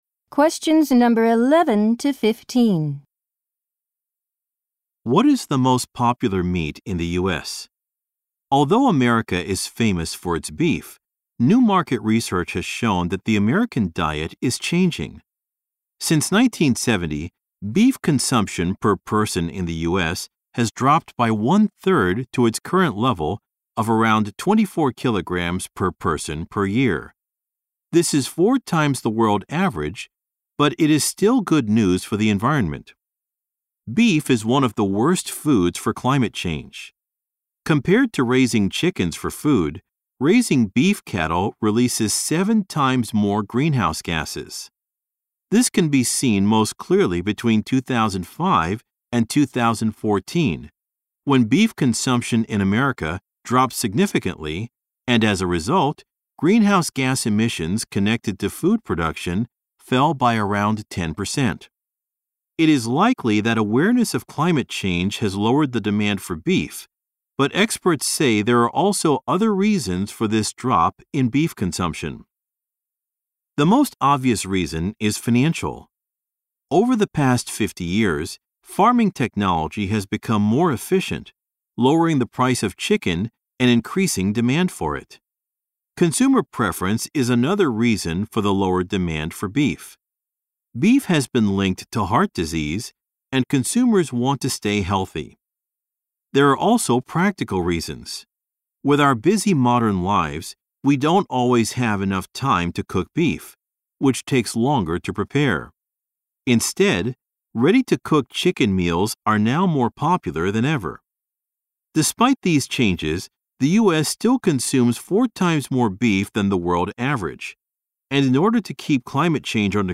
○共通テストの出題音声の大半を占める米英の話者の発話に慣れることを第一と考え，音声はアメリカ（北米）英語とイギリス英語で収録。
第4問形式：【第1回】第2問A　問12～15 （イギリス英語）